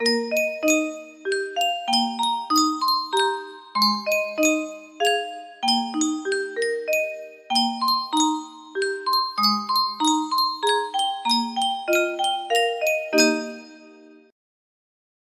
Yunsheng Music Box - Akatonbo 1117 music box melody
Full range 60